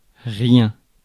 ÄäntäminenFrance (Paris):
• IPA: [ʁi.ɛ̃]